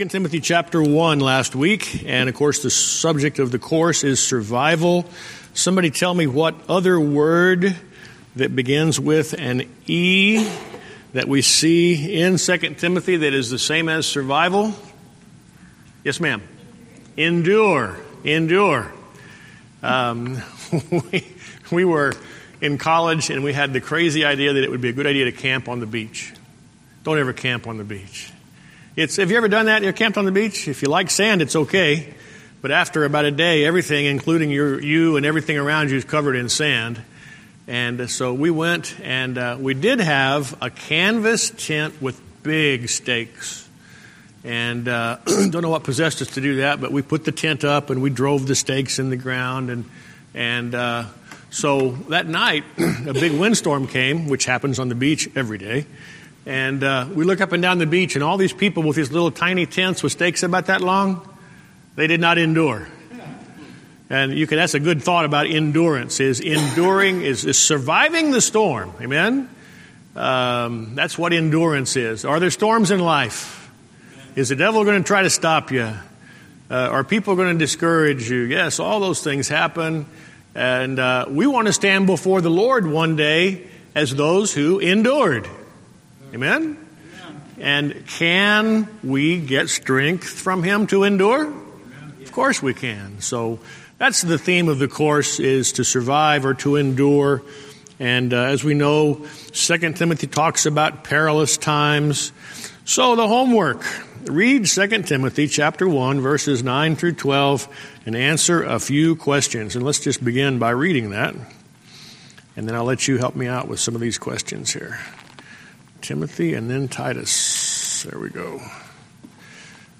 Sunday School Recordings